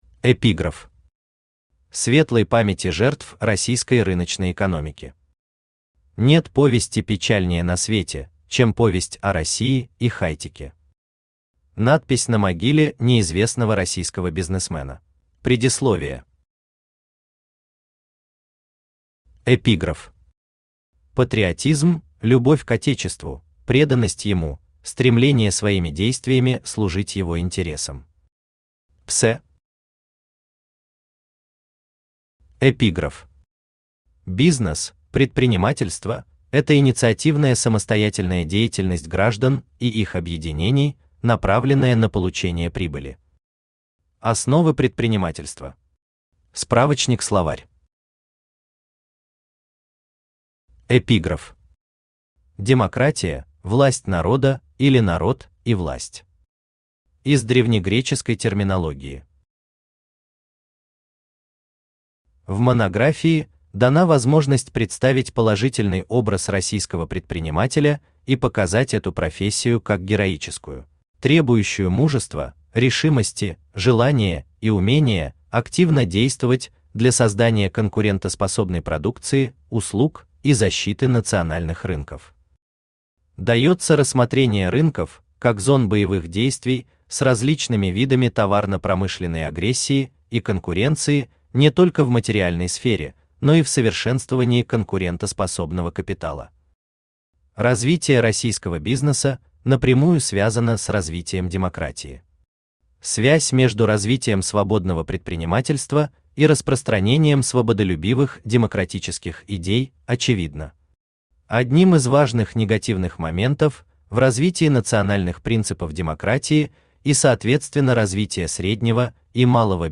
Аудиокнига Философия российского бизнеса | Библиотека аудиокниг